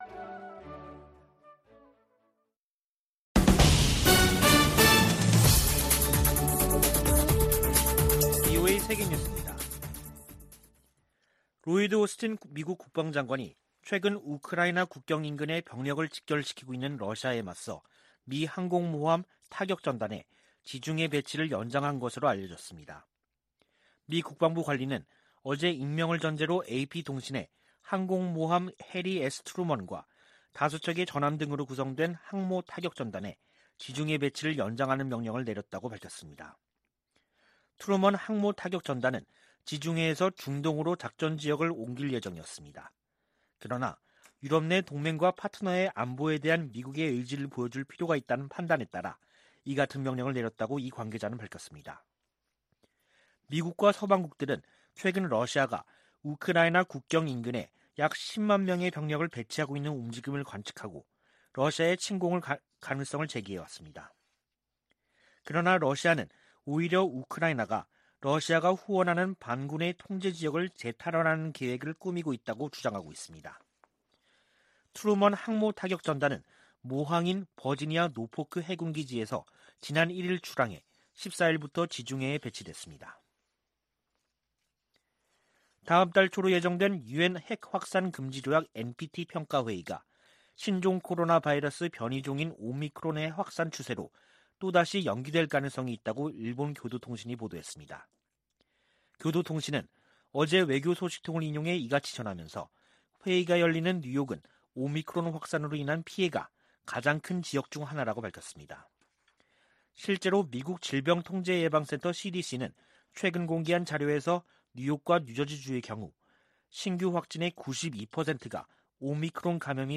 VOA 한국어 간판 뉴스 프로그램 '뉴스 투데이', 2021년 12월 29일 2부 방송입니다. 미 국무부는 한반도의 항구적 평화 달성을 위해 북한과의 외교에 전념하고 있다고 밝혔습니다. 정의용 한국 외교부 장관은 미-한 간 종전선언 문안 조율이 마무리됐다고 밝혔습니다. 조 바이든 미국 대통령이 역대 최대 규모 국방예산을 담은 2022국방수권법안에 서명했습니다.